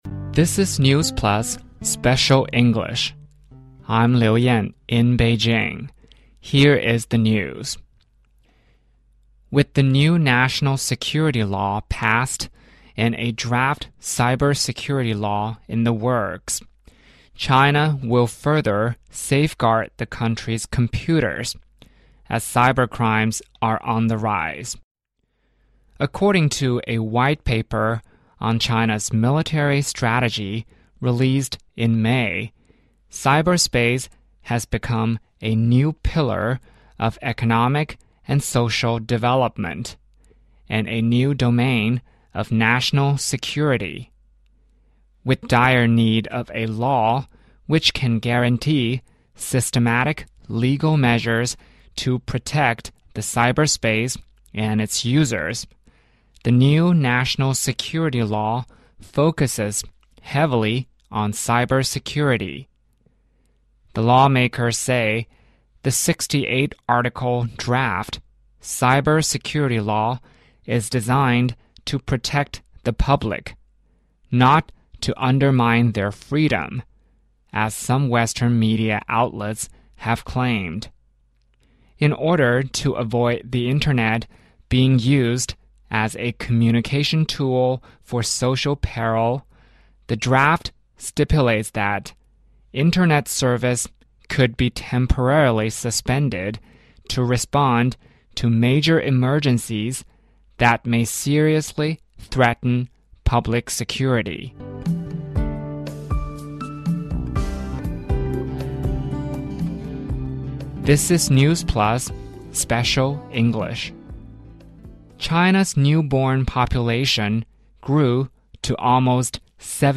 News
News Plus慢速英语:网络安全法草案规定重大突发事件可限网 去年我国出生人口增加